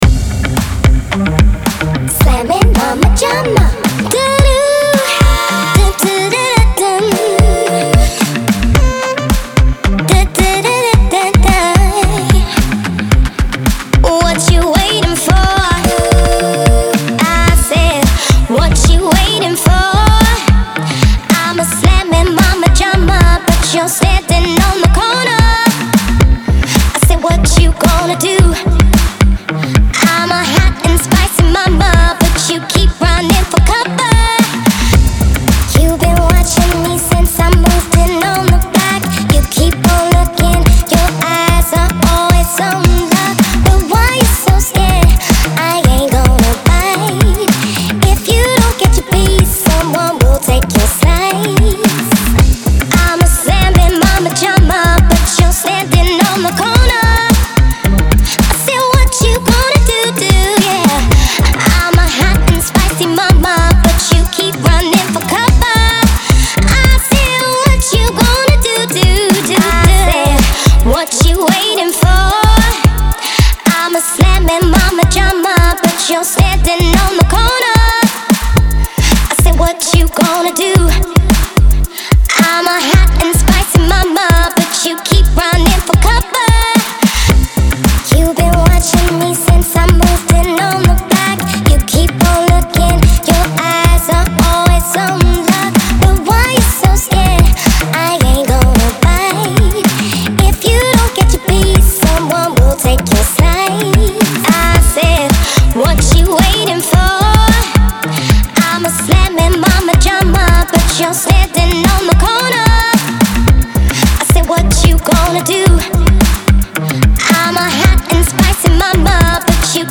• Категория: Synthwave